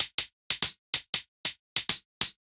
Index of /musicradar/ultimate-hihat-samples/95bpm
UHH_ElectroHatB_95-05.wav